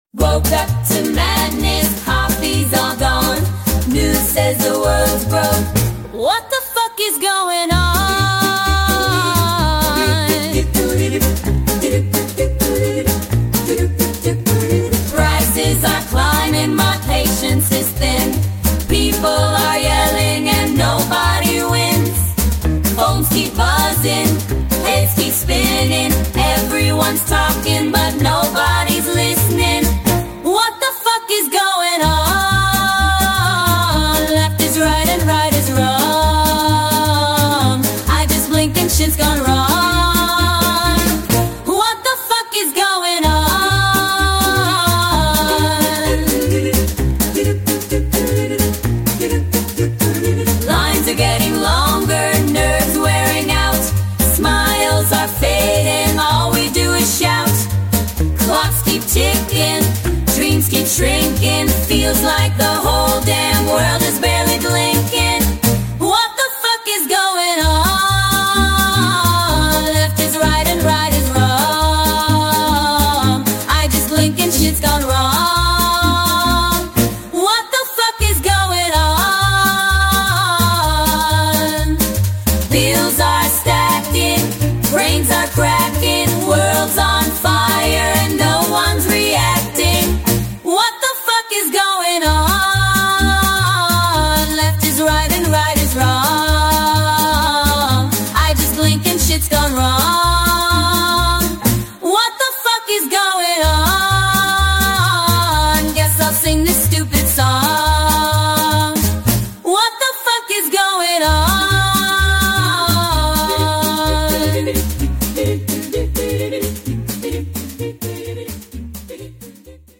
1950s Inspired Parody Music
AI generated cover